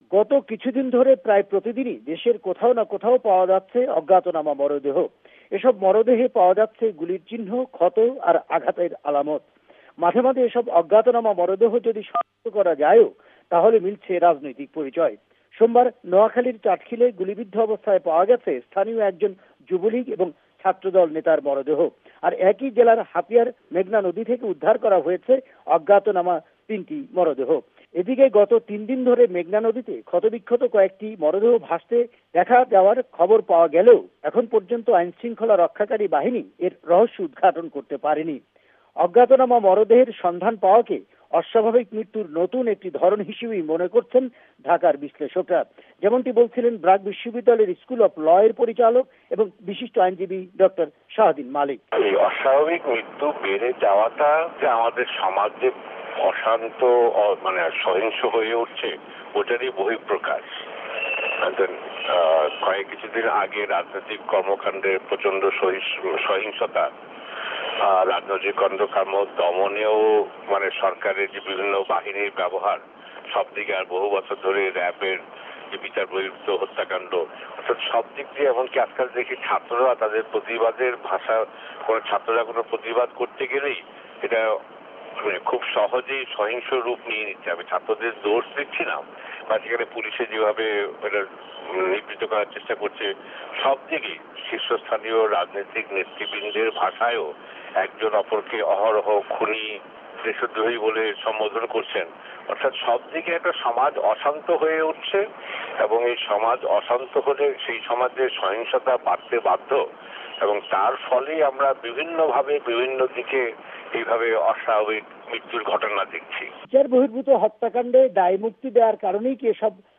ত্রাসের নবতর এ প্রবণতা নিয়ে উদ্বিগ্ন মানুষ।অস্বাভাবিক এসব মৃত্যু আর উপজেলা নির্বাচনের অনিয়ম সম্পর্কে কথা বলেছেন-বিশ্লেষন করেছেন ব্র্যাক বিশ্বিদ্যালয়ের অধ্যাপক-বিশিষ্ট আইনজিবী ডক্টর শাদীন মালিক।